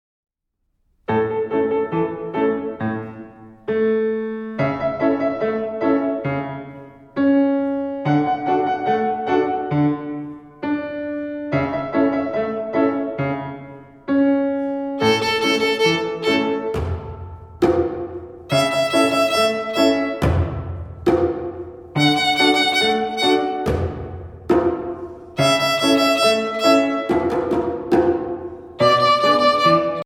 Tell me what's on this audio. Voicing: Violin Method